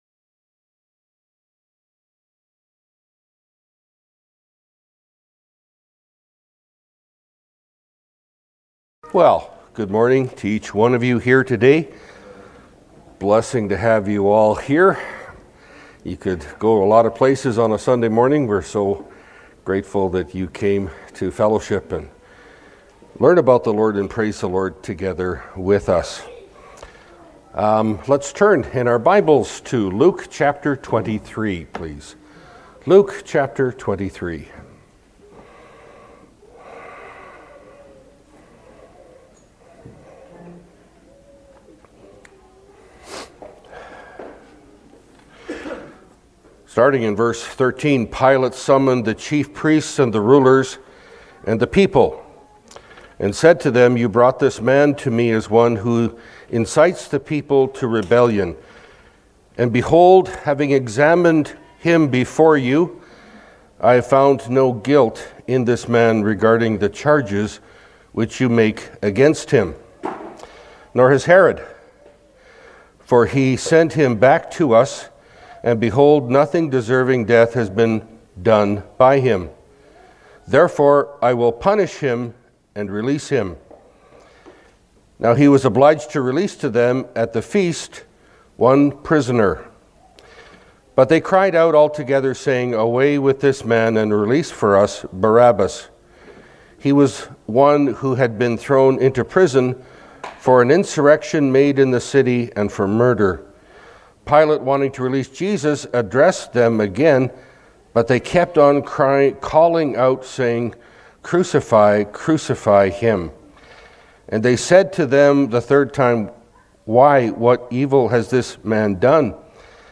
Pulpit Sermons Key Passage